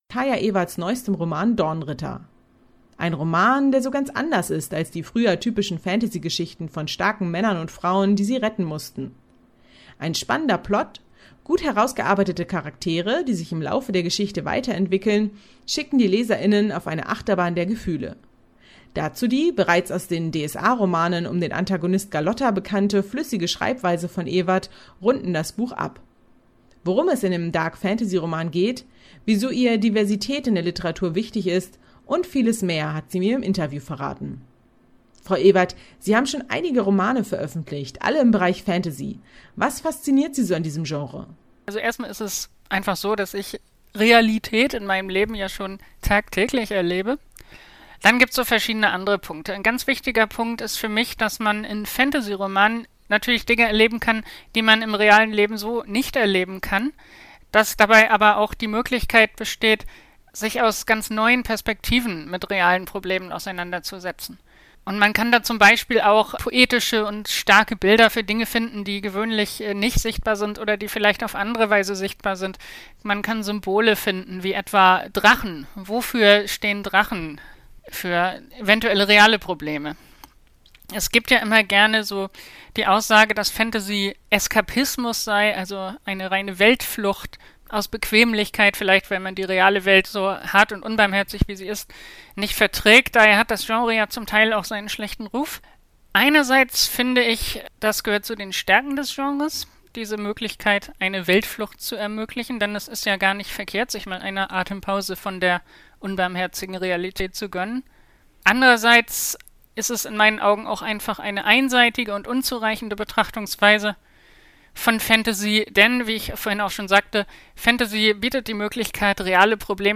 Interview StadtRadio Göttingen